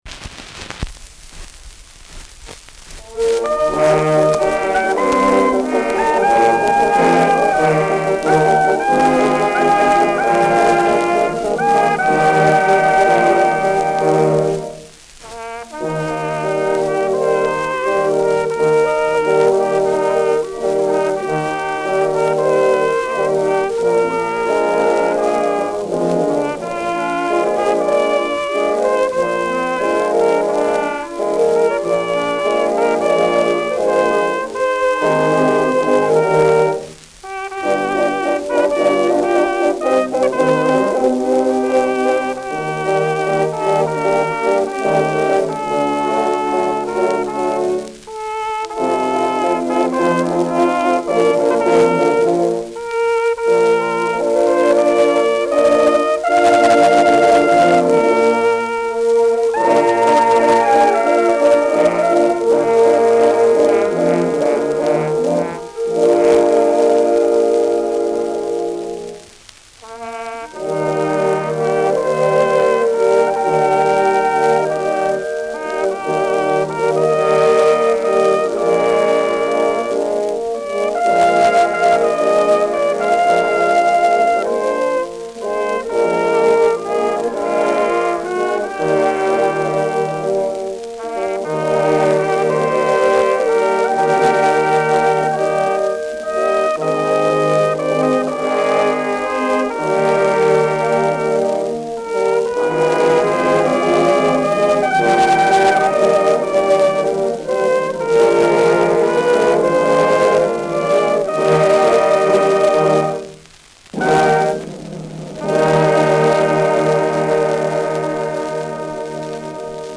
Schellackplatte
Abgespielt : Teils leicht verzerrt
Möbelgeräusch am Ende.